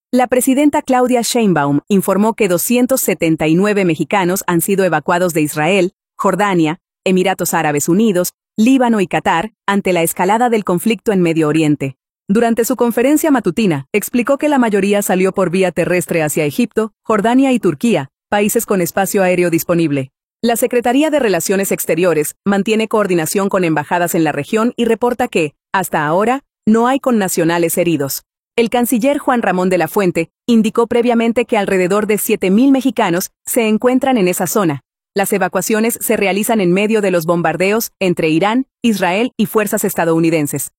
La presidenta Claudia Sheinbaum Pardo informó que 279 mexicanos han sido evacuados de Israel, Jordania, Emiratos Árabes Unidos, Líbano y Qatar ante la escalada del conflicto en Medio Oriente. Durante su conferencia matutina, explicó que la mayoría salió por vía terrestre hacia Egipto, Jordania y Turquía, países con espacio aéreo disponible.